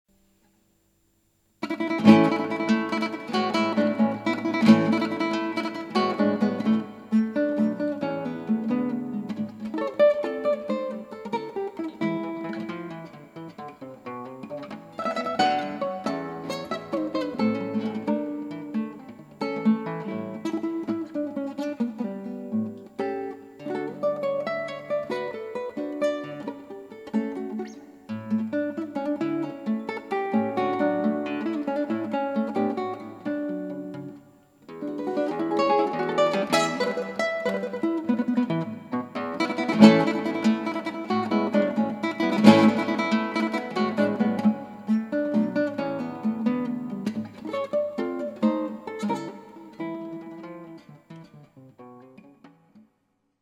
クラシックギター　ストリーミング　コンサート
ぜんぜん弾けてませんねぇ。